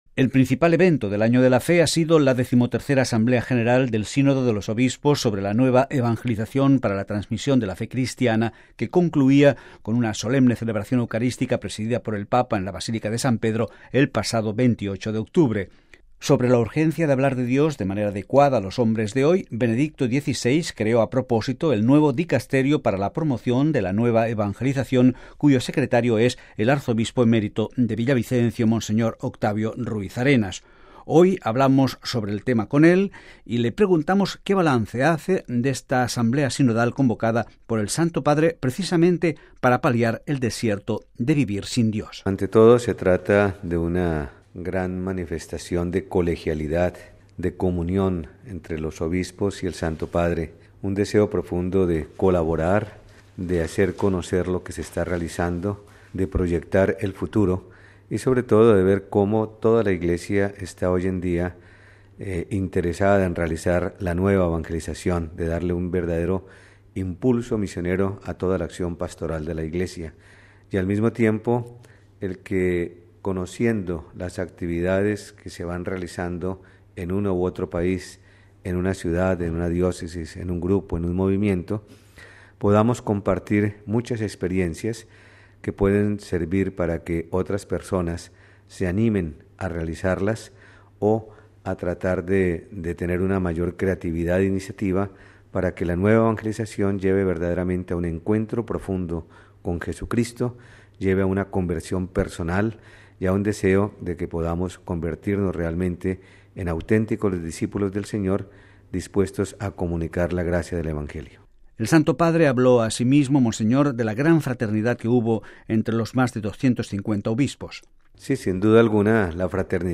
Hoy hablamos sobre el tema con él y le preguntamos qué balance hace de esta asamblea sinodal convocada por el Santo Padre precisamente para paliar el “desierto de vivir sin Dios”.